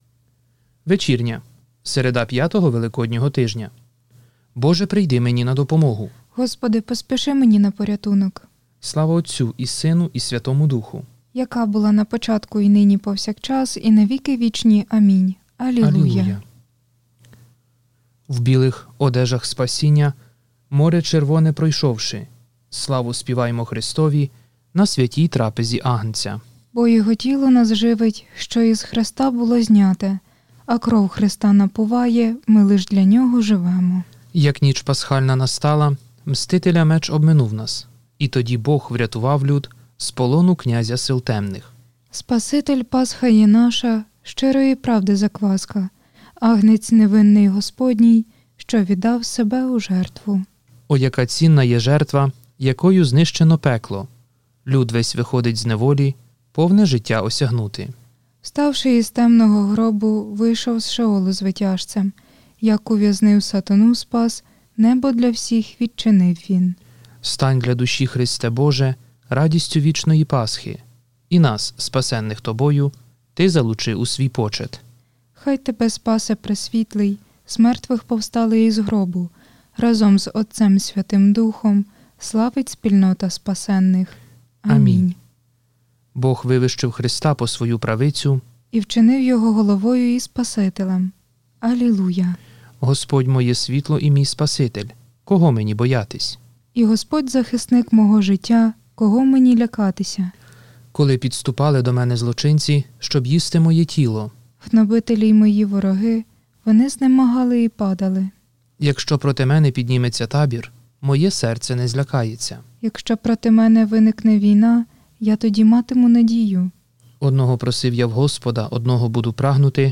ВЕЧІРНЯ